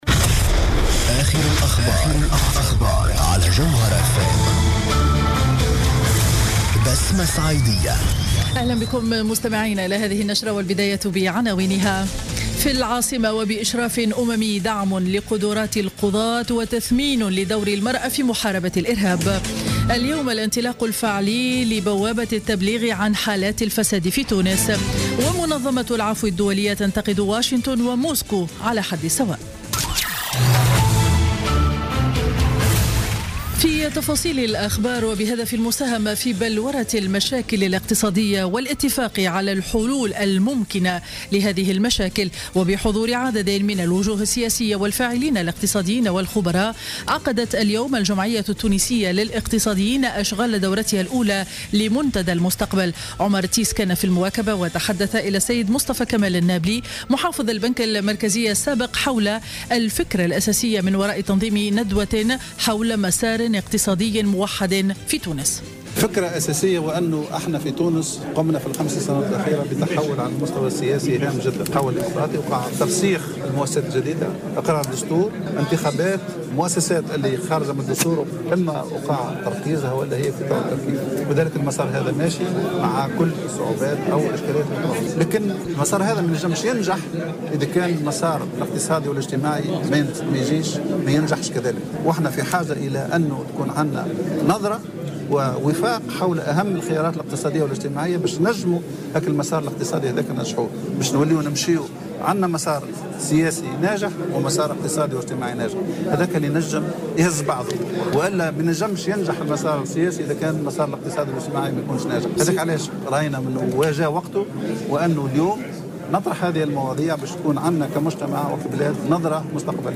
نشرة أخبار منتصف النهار ليوم الأربعاء 24 فيفري 2016